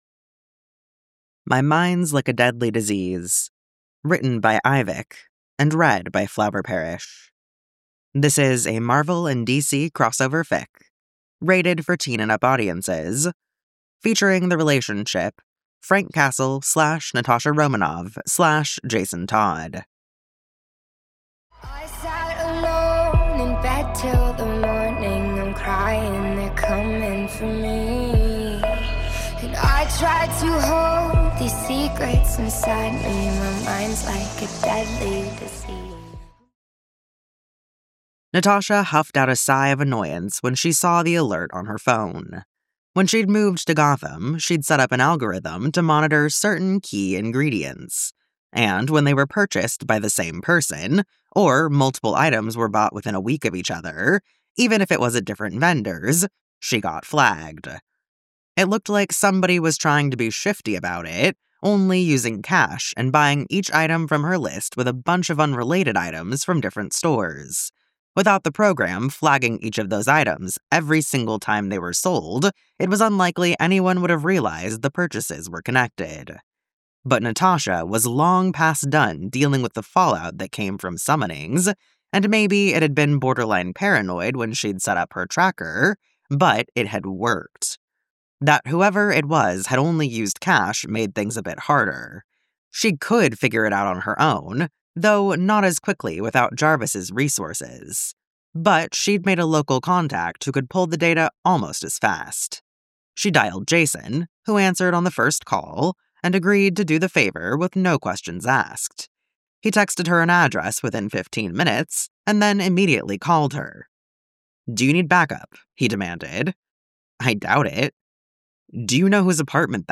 [Podfic] My mind's like a deadly disease